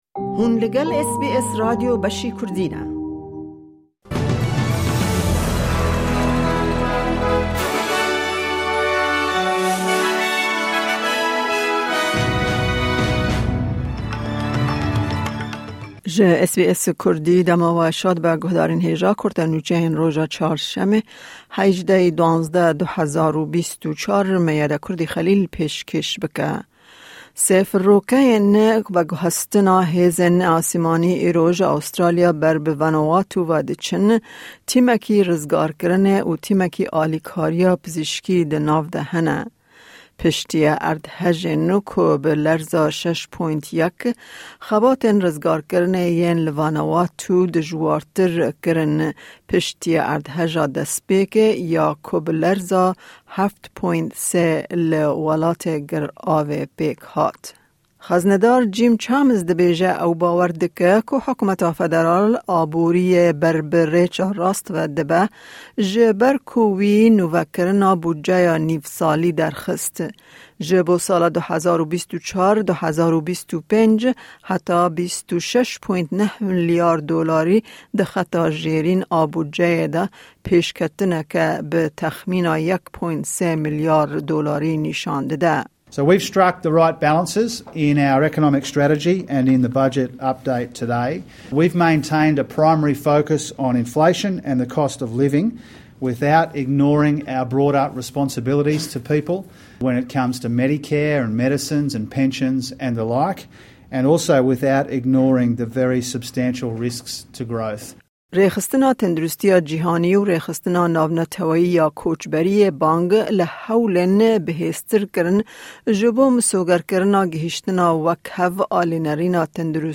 Kurte Nûçeyên roja Çarşemê 18î Kanûna 2024